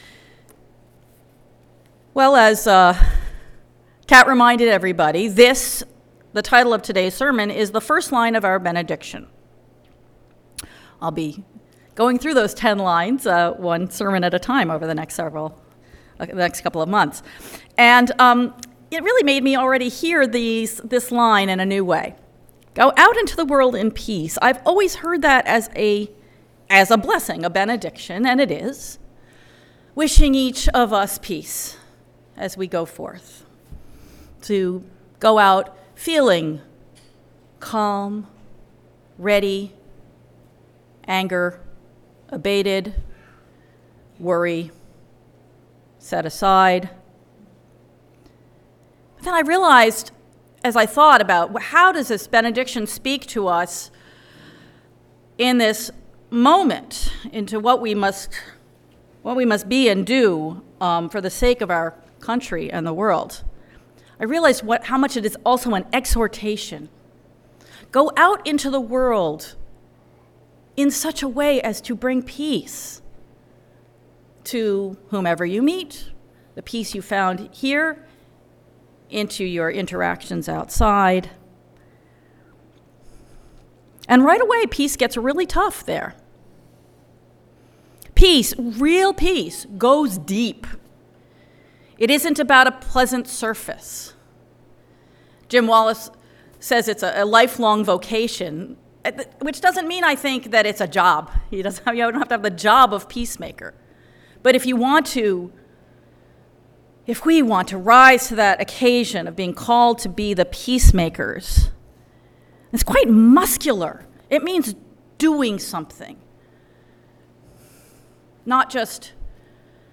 (This is the first in a series of sermons based on our benediction.)